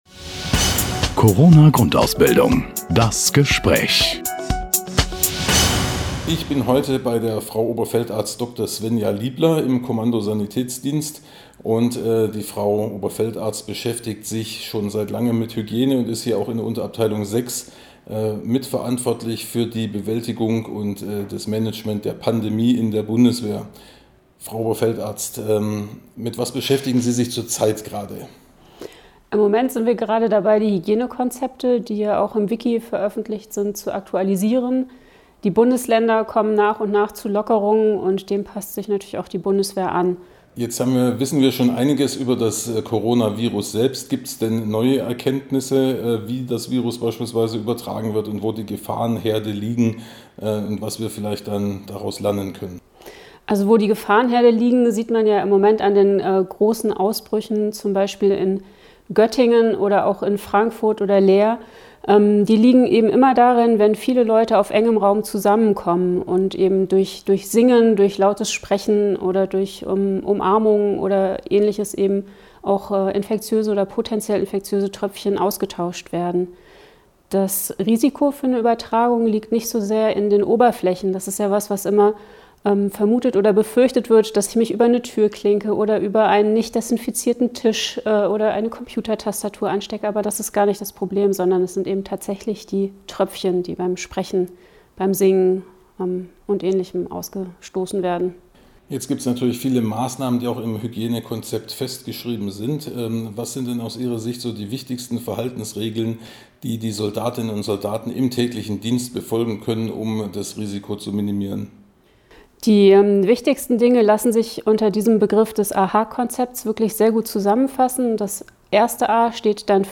im Gespräch über das Coronavirus und notwendige Hygiene- und Schutzmaßnahmen